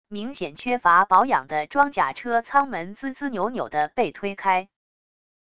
明显缺乏保养的装甲车舱门吱吱扭扭的被推开wav音频